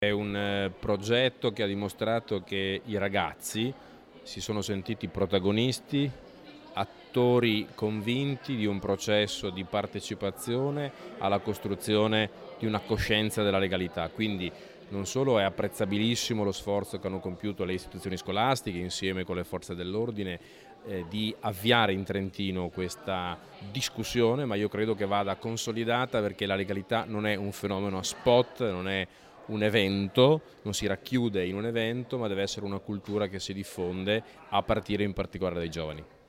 L'intervista al vicepresidente della Provincia Alessandro Olivi
OLIVI_INTERVISTA_serata_sociale.mp3